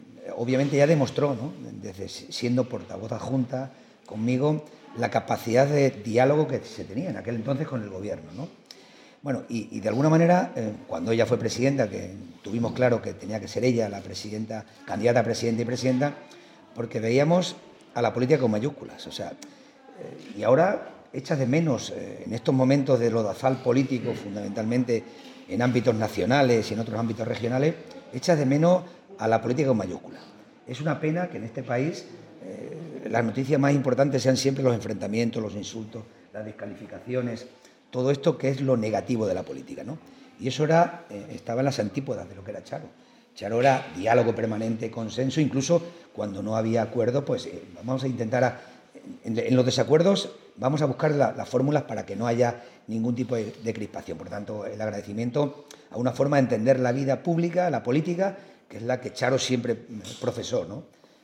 En un sencillo pero emotivo acto celebrado en el Salón Noble del Ayuntamiento de Plasencia se ha hecho entrega a la familia de Charo Cordero, presidenta de la Diputación de Cáceres, una copia del azulejo que se instalará en los próximos días en el entorno de la Puerta de Trujillo, concretamente en la parte superior de los aparcamientos públicos de la Calle Eulogio González, espacio que pasará a denominarse “Plaza María del Rosario Cordero Martín”.
CORTES DE VOZ